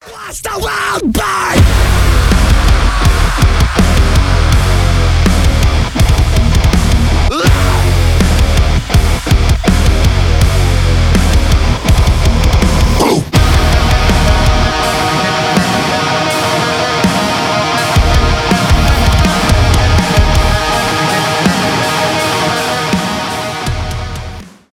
жесткие , мощные , взрывные , metalcore
металкор